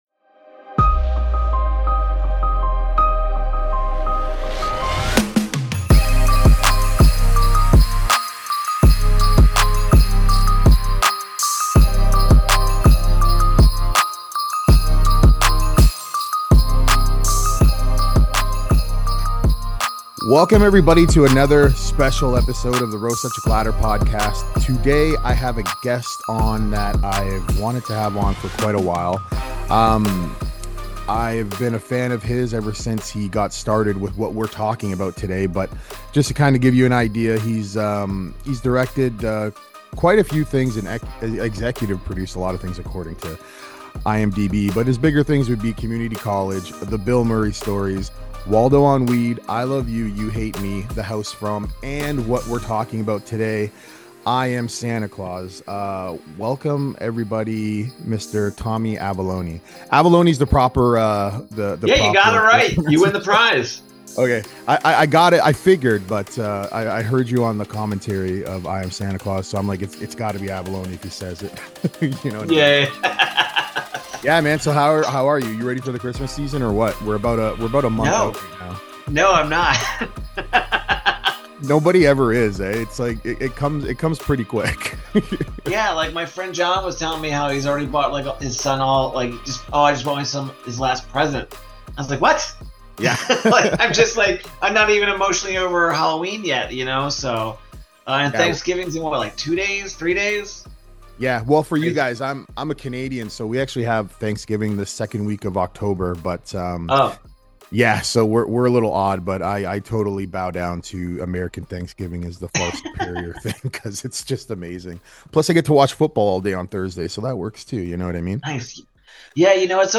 Today I have a super special guest.
I have been wanting to interview this man for a long time now.